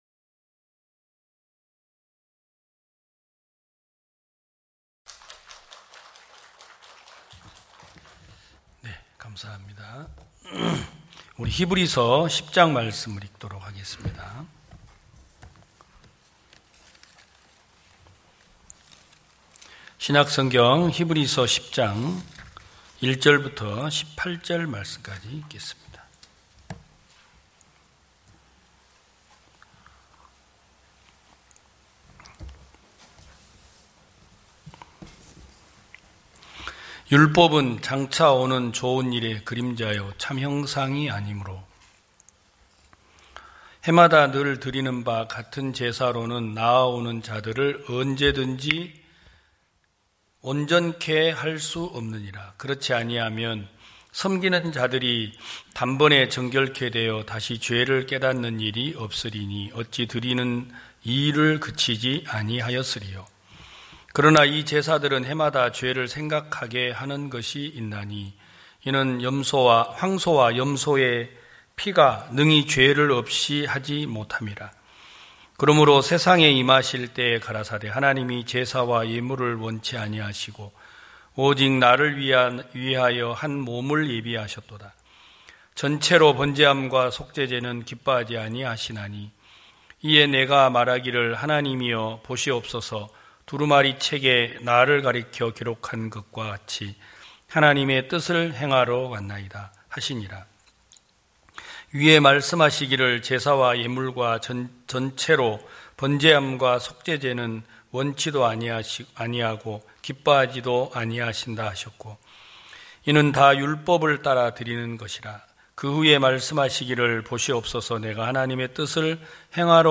2021년 10월 17일 기쁜소식부산대연교회 주일오전예배
성도들이 모두 교회에 모여 말씀을 듣는 주일 예배의 설교는, 한 주간 우리 마음을 채웠던 생각을 내려두고 하나님의 말씀으로 가득 채우는 시간입니다.